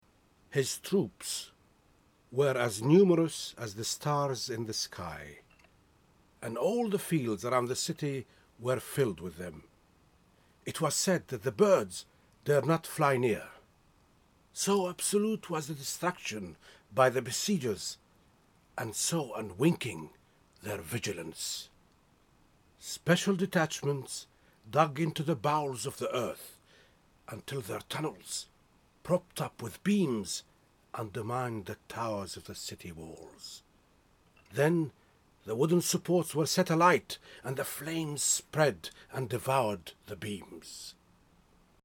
ARABIC. Actor and Voice Artist.